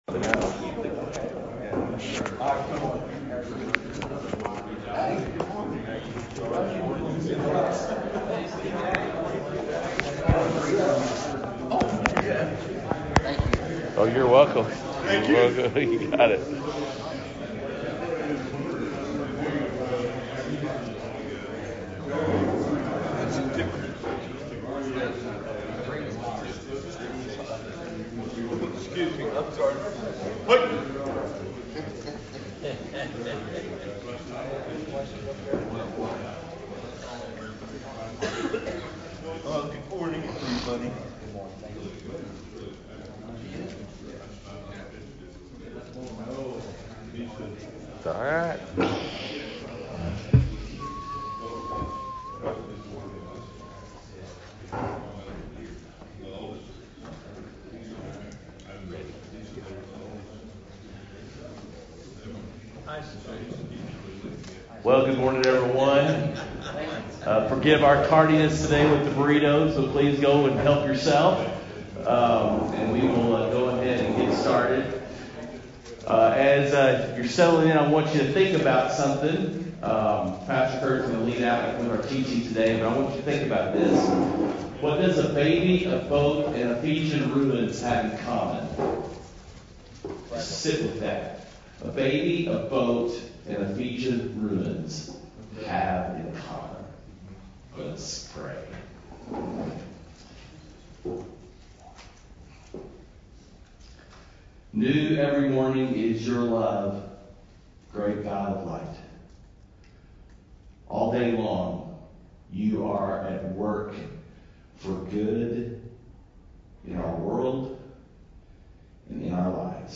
Men’s Breakfast Bible Study 10/13/20
Mens-Bible-Study-10_13.mp3